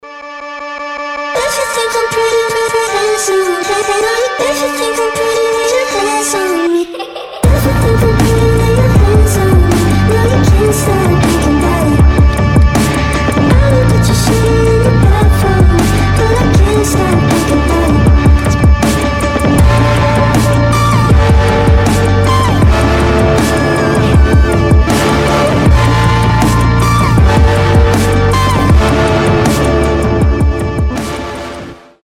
поп
alternative
rnb